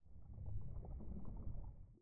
Add footsteps for water - swimming sounds.
I added a 4th sample that's essentially a nearly-quiet version of one of the prior sounds. This combined with the rate of footstep sounds results in a randomly changing underwater sound that blends in and out somewhat nicely.
default_water_footstep.4.ogg